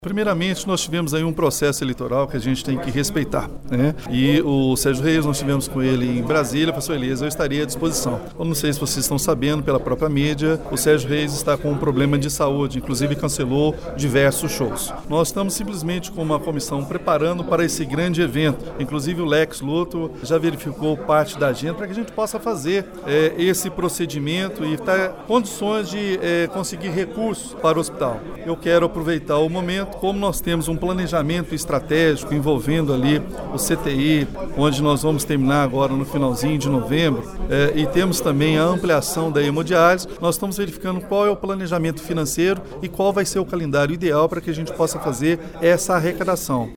O chefe do Poder Executivo explicou que foi preciso aguardar o período eleitoral porque o cantor é deputado federal. O artista também passou por alguns problemas de saúde e está se recuperando: